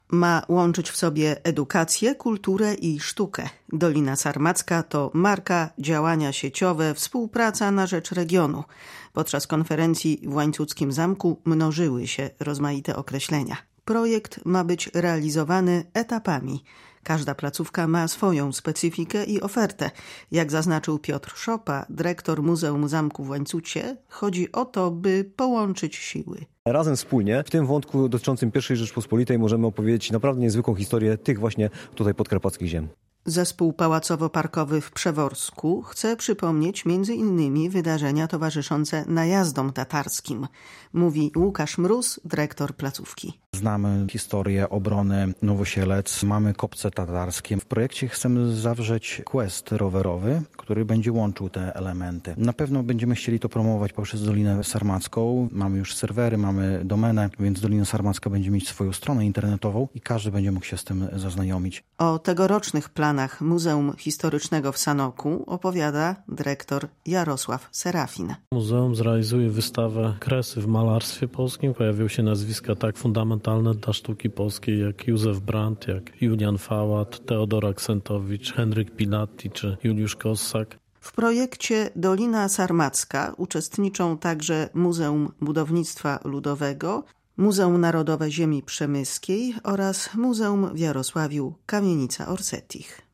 Muzea łączą siły w nowym projekcie historycznym • Relacje reporterskie • Polskie Radio Rzeszów